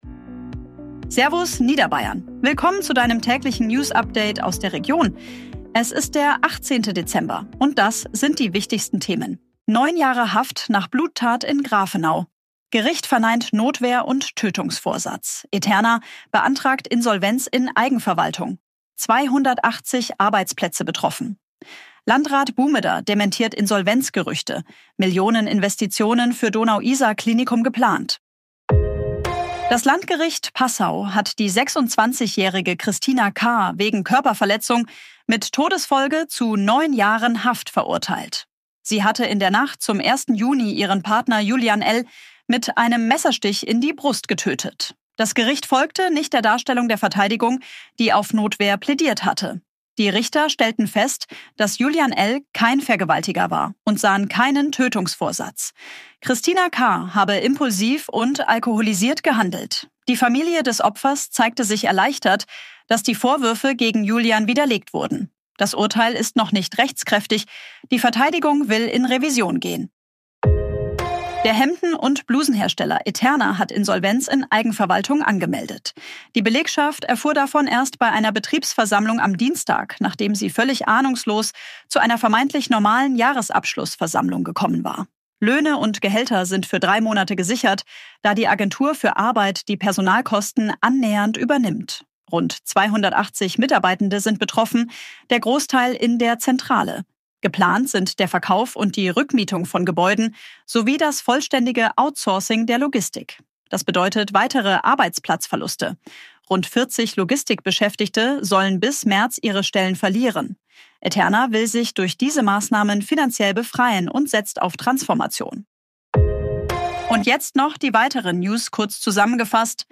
Tägliche Nachrichten aus deiner Region
Update wurde mit Unterstützung künstlicher Intelligenz auf Basis